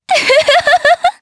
Lewsia_B-Vox_Happy3_jp.wav